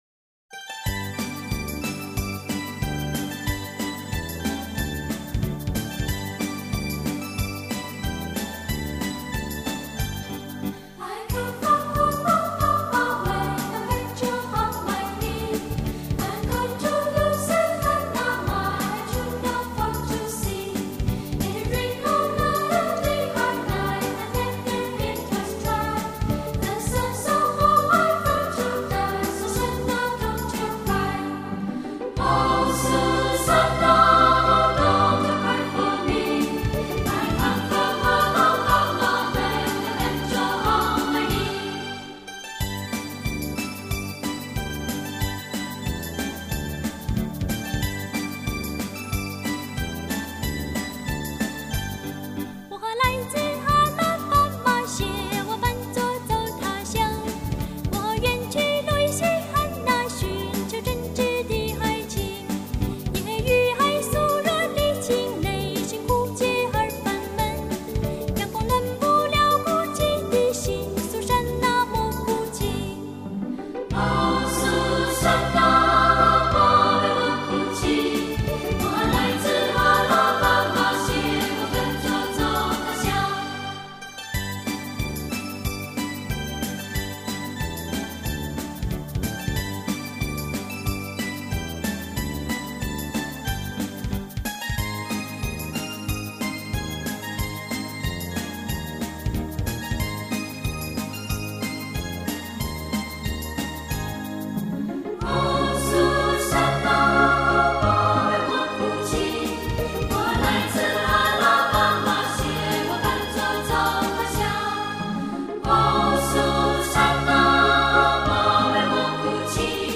童声合唱是一种广受欢迎的形式，它包含错落的声部构成
与和谐悠扬的齐唱。